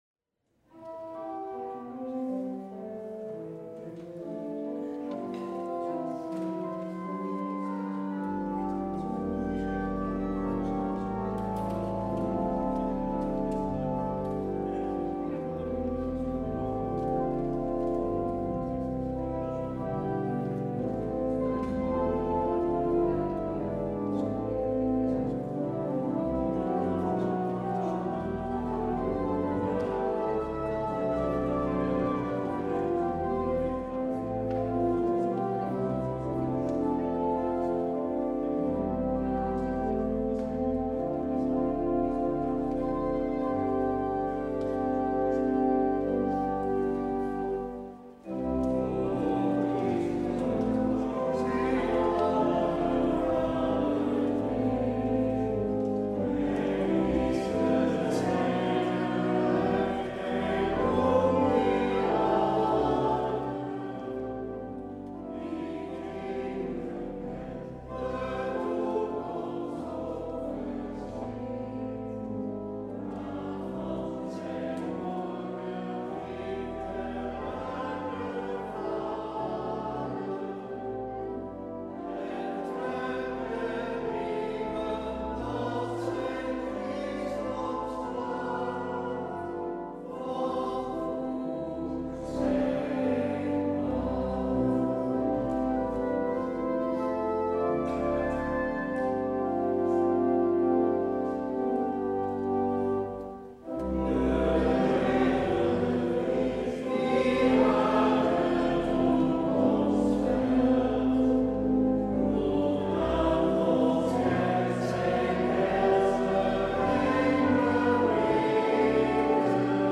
 Beluister deze kerkdienst hier: Alle-Dag-Kerk 22 januari 2025 Alle-Dag-Kerk https
Daar gaat het over in deze middagpauzedienst.